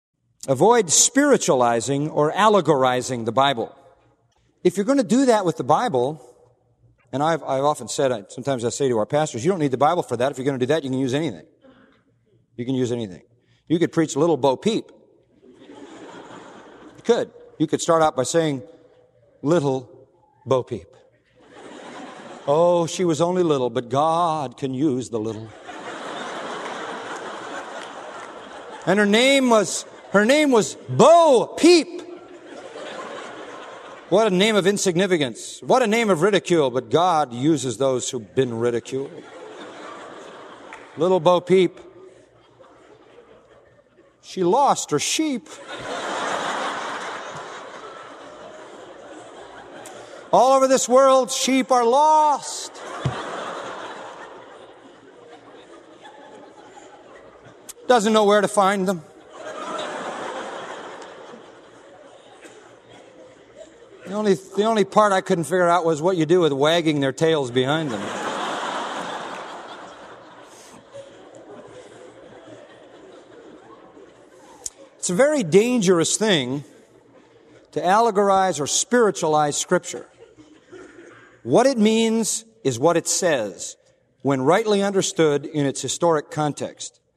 And he did a 90-second parody of how that could be done using the style of preaching so many contemporary evangelicals seem to favor.
John MacArthur "exegeting" Little Bo Peep.